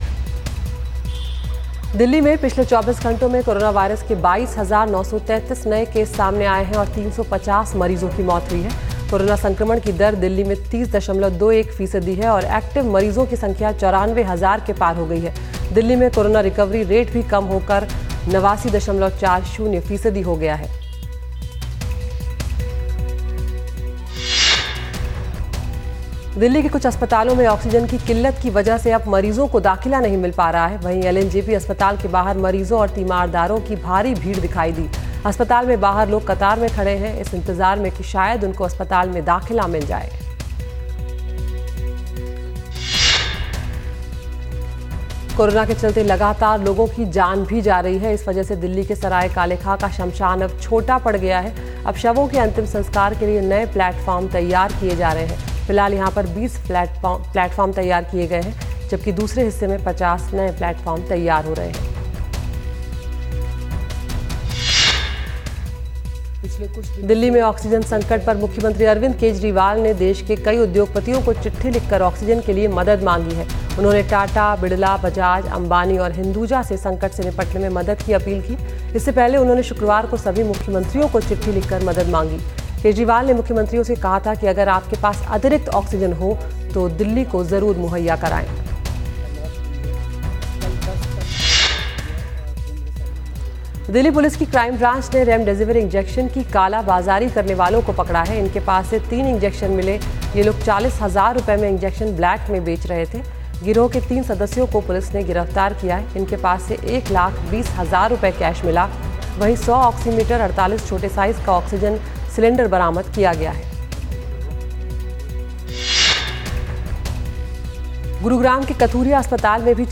… continue reading 19 एपिसोडस # दैनिक समाचार # समाचार